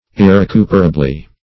-- Ir`re*cu"per*a*bly , adv.